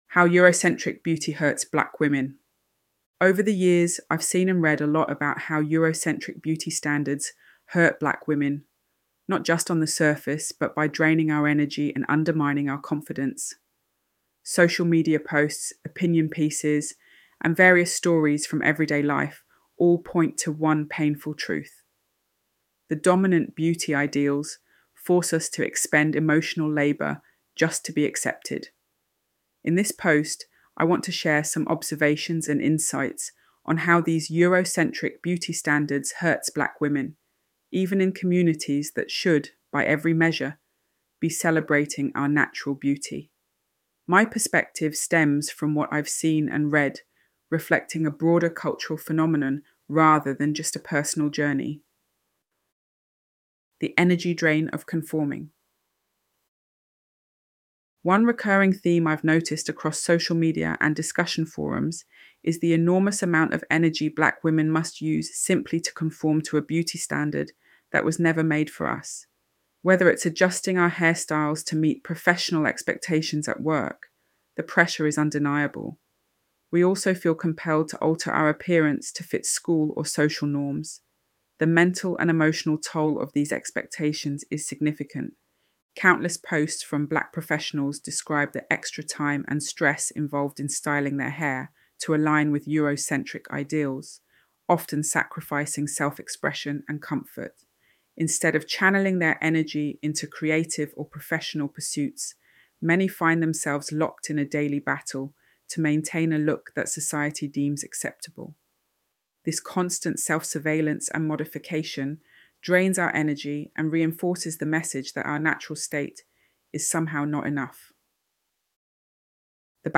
ElevenLabs_How_Eurocentric_Beauty_Hurts_Black_Women.mp3